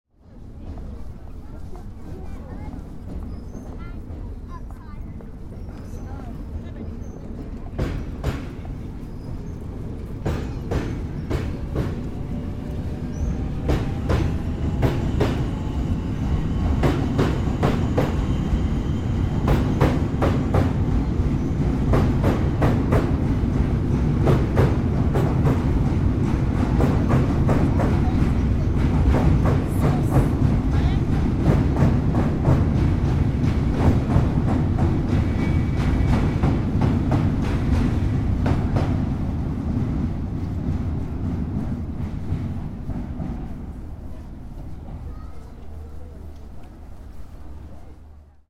Train crossing Hungerford bridge
A train coming from the Charing Cross station passes the Hungerford Bridge. Recorded from the northern Golden Jubilee bridge using a Tascam DR-05X device (internal mics) in April 2022.